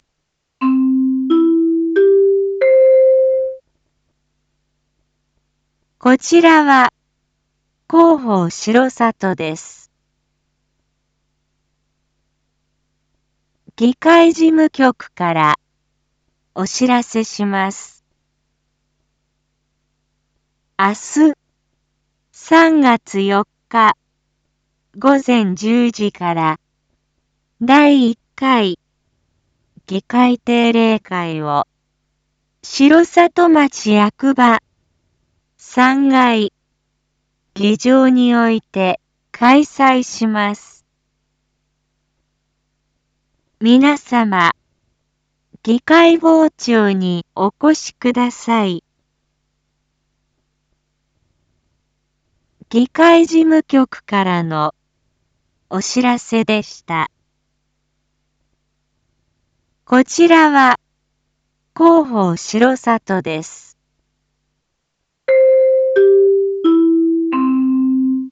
Back Home 一般放送情報 音声放送 再生 一般放送情報 登録日時：2025-03-03 19:01:09 タイトル：議会定例会（１） インフォメーション：こちらは広報しろさとです。